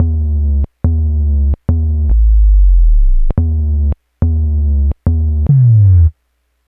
bass04.wav